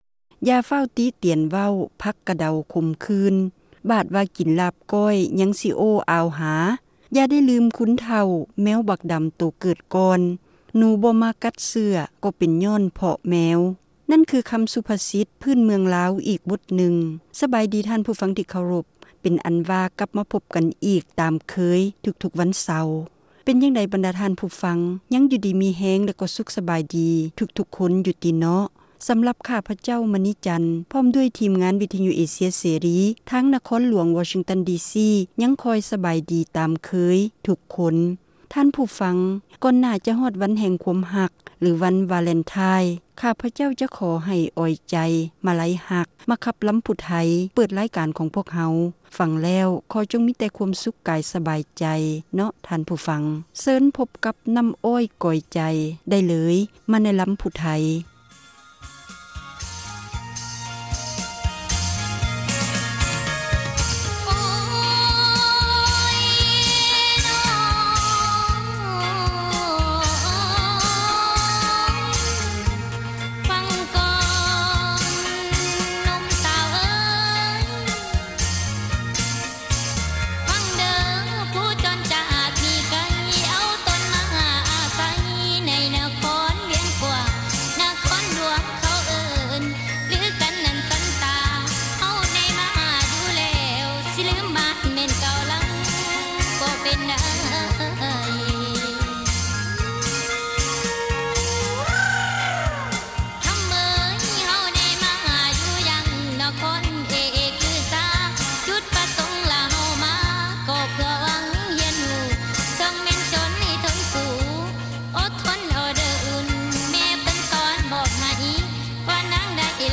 ລໍາ ແຕ່ເໜືອ ຕລອດໃຕ້: ວັນທີ 8 ກຸມພາ 2008